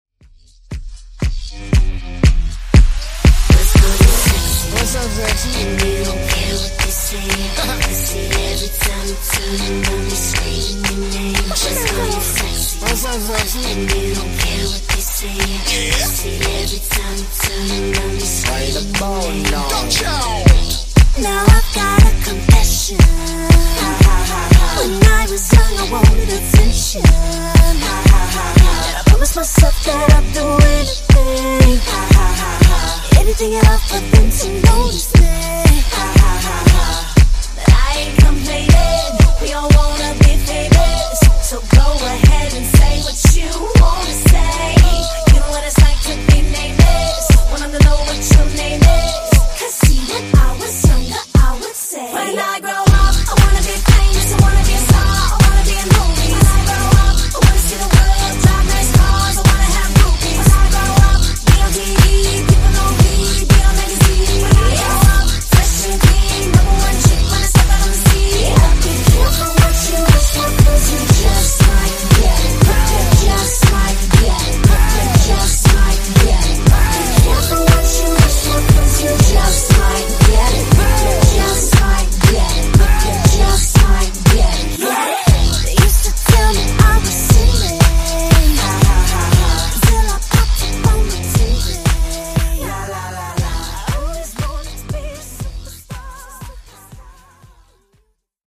Genre: RE-DRUM
Dirty BPM: 71 Time